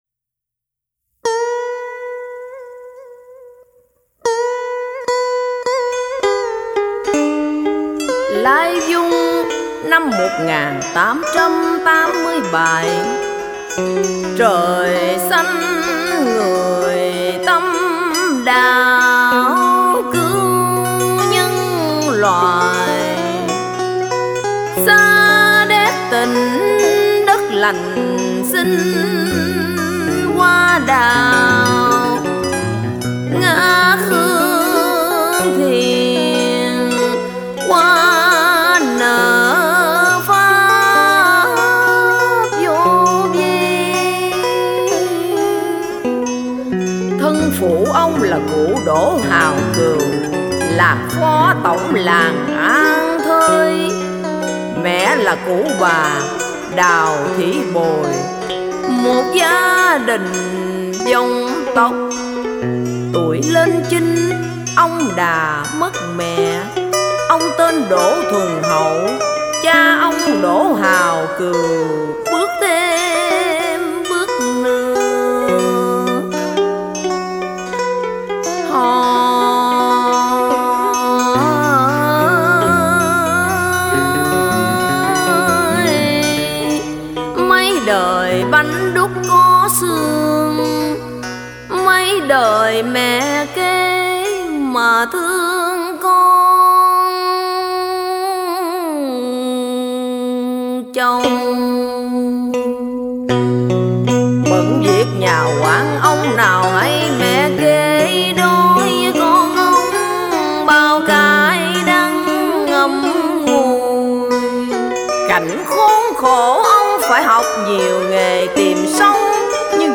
Dân Ca & Cải Lương